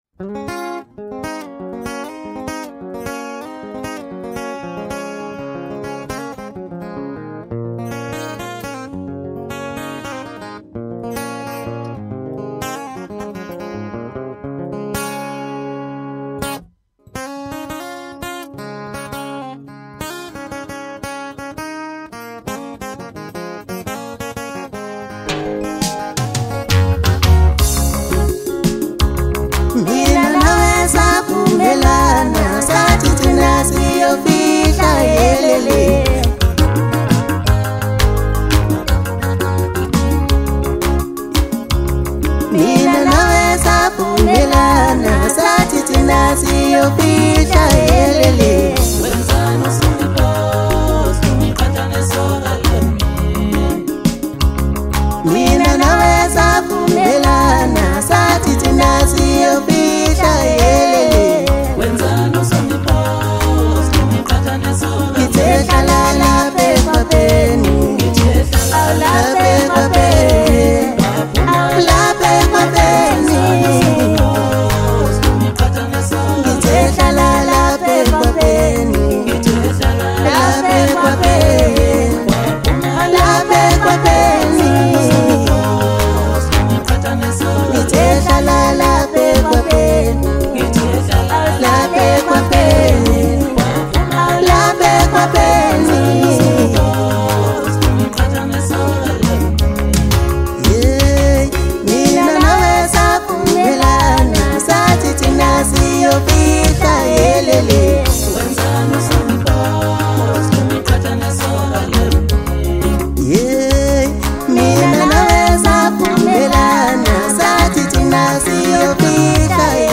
Home » Maskandi » DJ Mix » Hip Hop
South African singer-songwriter